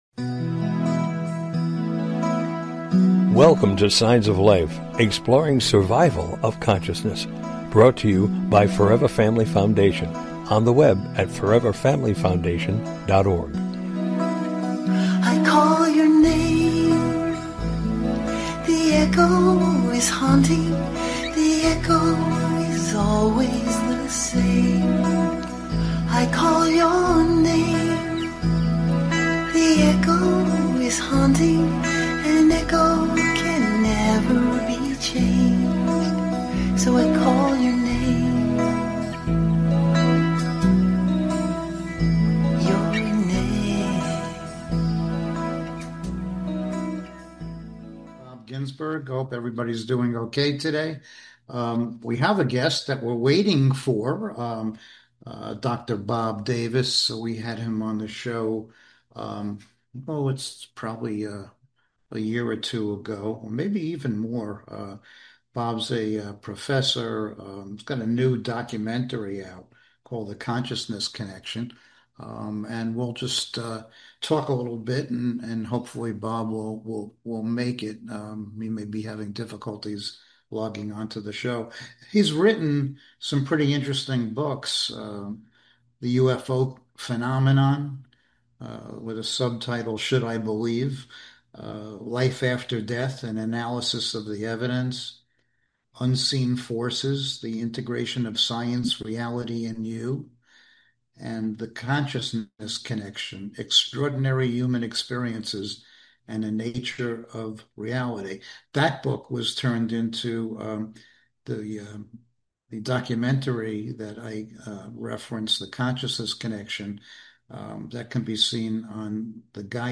Signs of Life Radio Show is a unique radio show dedicated to the exploration of Life After Death!